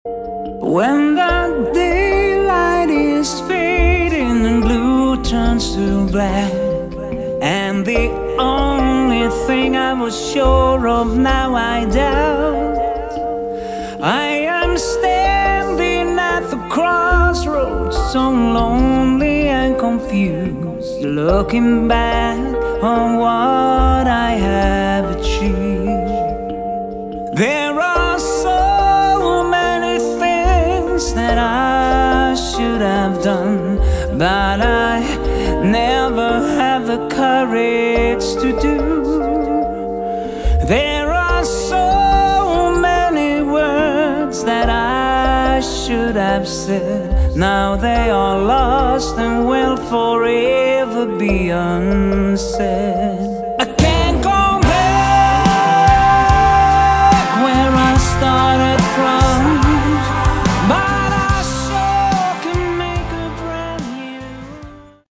Category: AOR / Melodic Rock
Lead Vocals, Backing Vocals, Keyboards